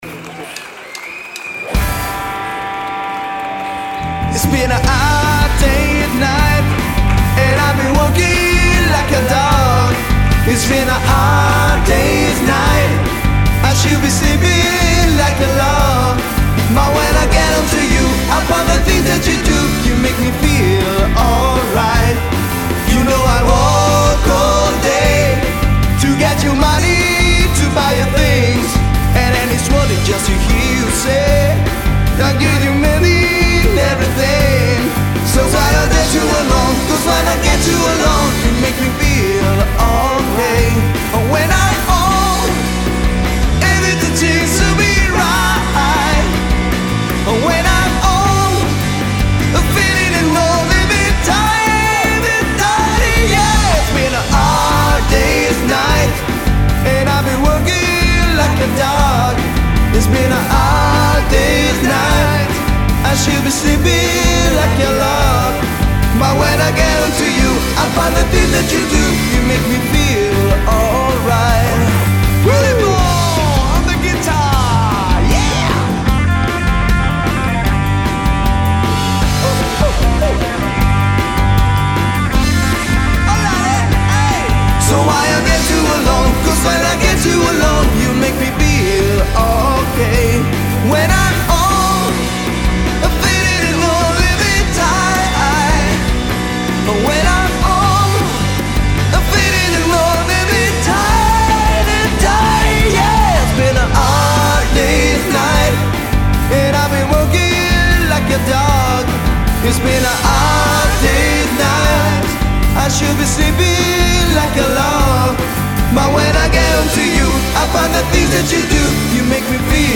Voce, Chitarra Acustica
Voce, Basso
Chitarre
Batteria
Cover pop-rock